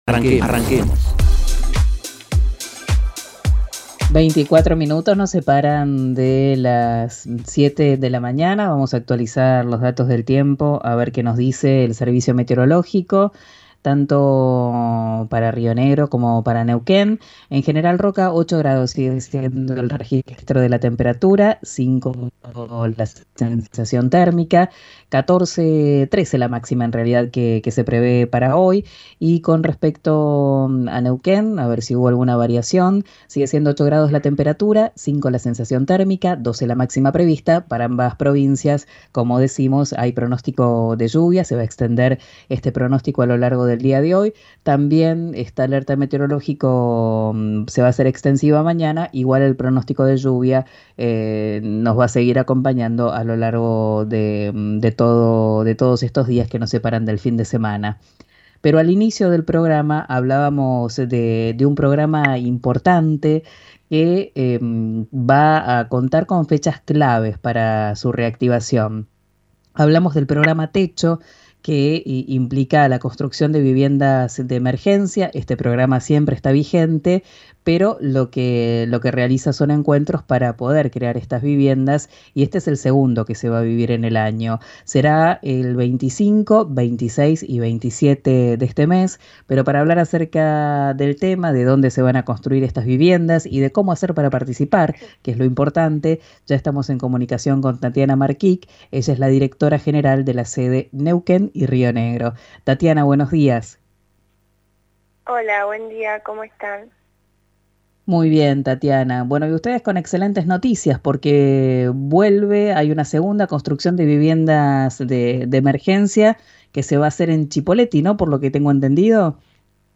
dialogó con Río Negro RADIO sobre los detalles de la iniciativa que se desarrollará el 25, 26 y 27 de agosto en Cipolletti.